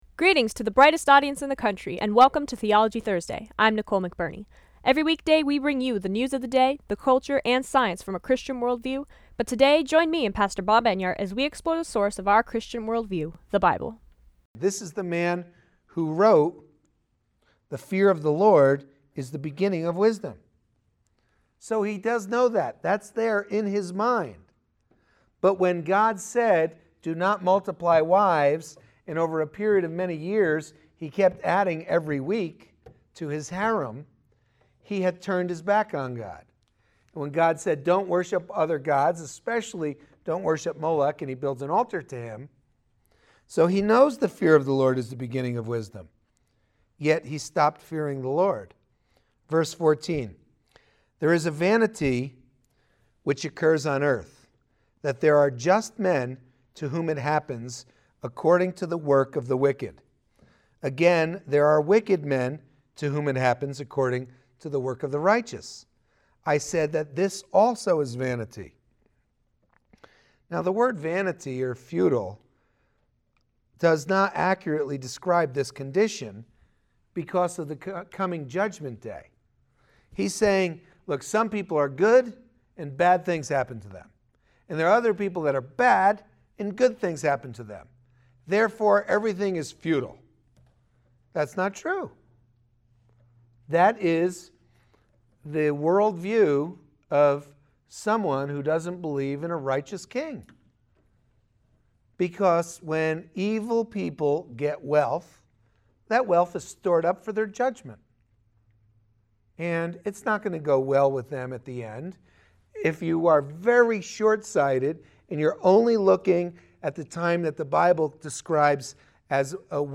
Friday's Broadcast